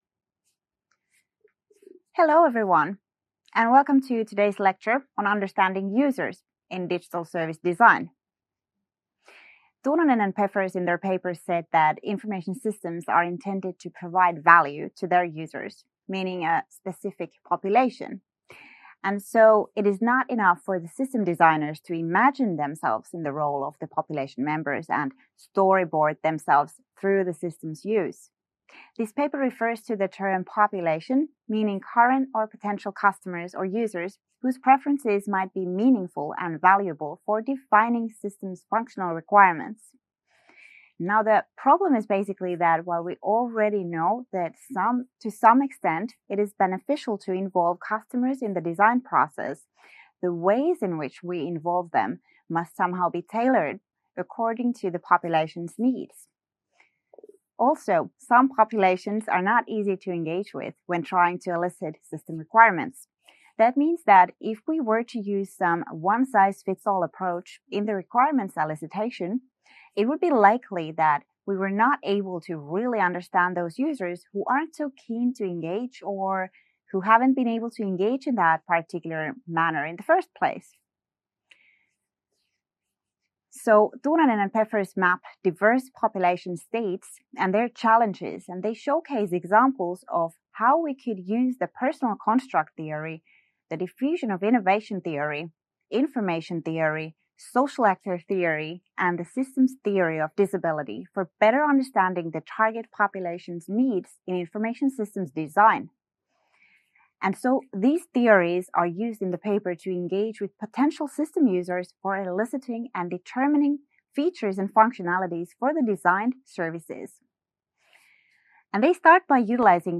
Week 2 Self-Study Video Lecture - Understanding Users in Digital Service Design